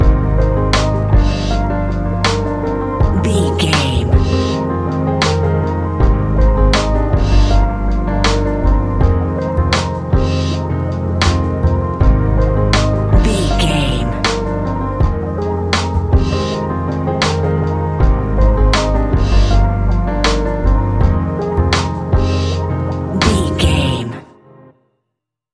Ionian/Major
F♯
laid back
Lounge
sparse
new age
chilled electronica
ambient
atmospheric
instrumentals